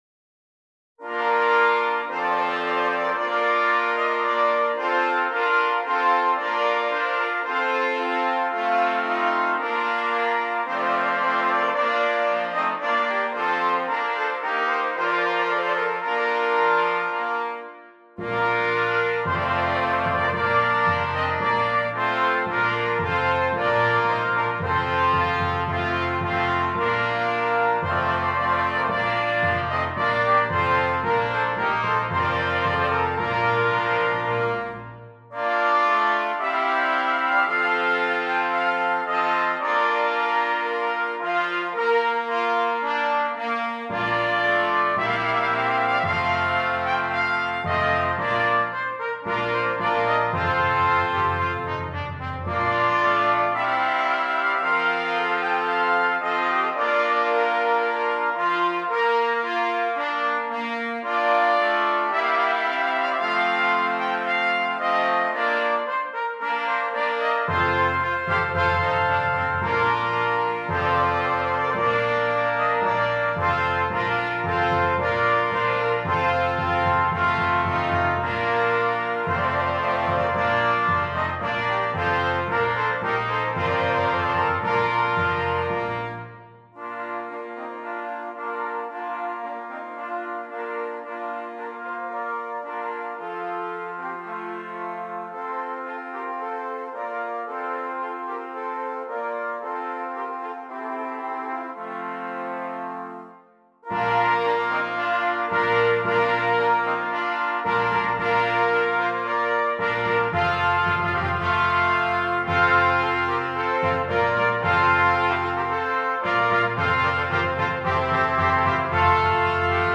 6 Trumpets